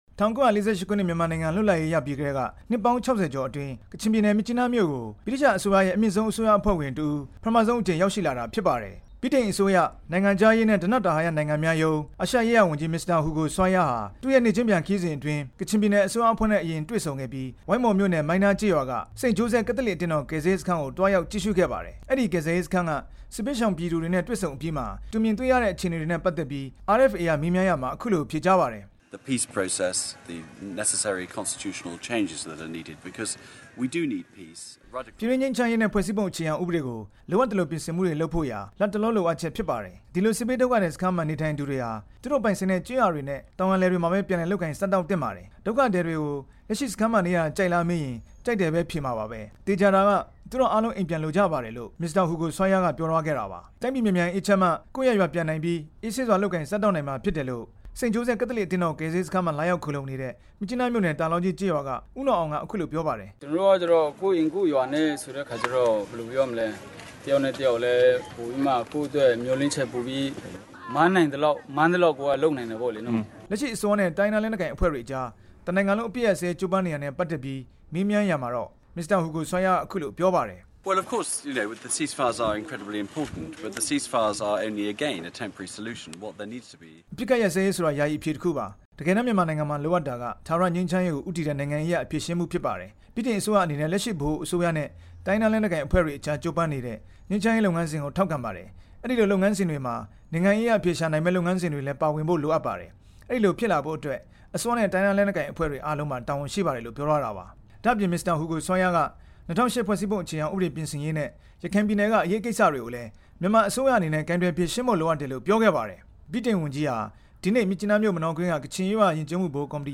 ကချင်ပြည်နယ် မြစ်ကြီးနားမြို့ကို ရောက်ရှိစဉ် ငြိမ်းချမ်းရေး လုပ်ငန်းစဉ်နဲ့ ပတ်သက်ပြီး RFA က မေးမြန်းရာမှာ  Mr Hugo Swire က အခုလို ပြောလိုက်တာပါ။